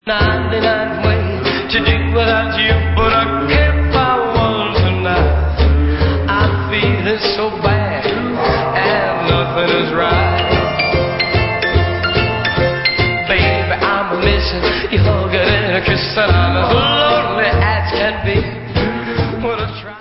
50's rock